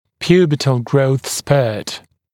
[‘pjuːbətl grəuθ spɜːt][‘пйу:бэтл гроус спё:т]пубертатный скачок роста